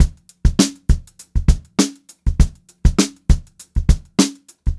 100STBEAT1-R.wav